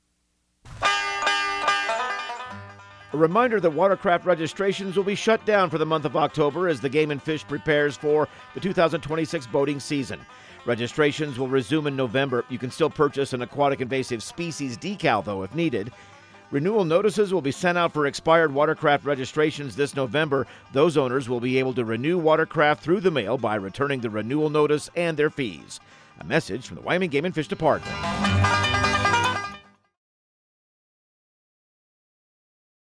Radio News | Week of September 22
Outdoor Tip/PSA